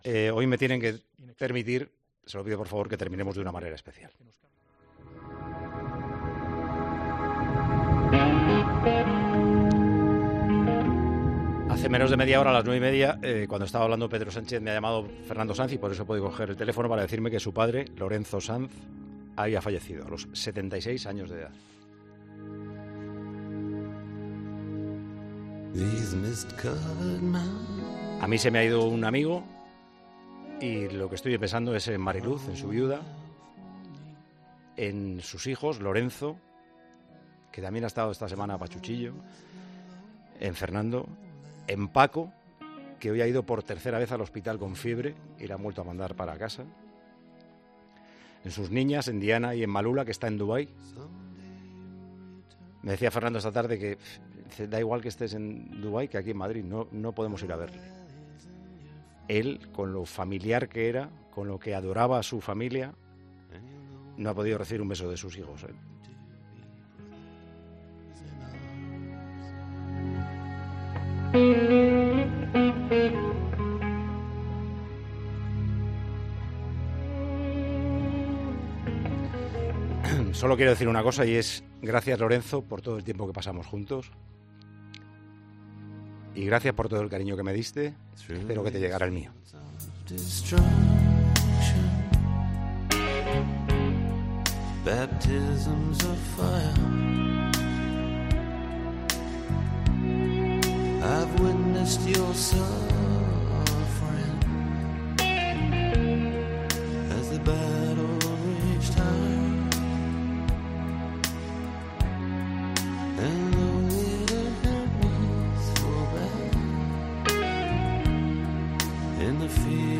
La emotiva despedida de Paco González a su amigo Lorenzo Sanz: "Gracias por todo el cariño que me diste"
Paco González se emocionó tras conocer el fallecimiento de Lorenzo Sanz: "Se me ha ido un amigo, que no ha podido recibir un beso de sus hijos".
Con Paco González, Manolo Lama y Juanma Castaño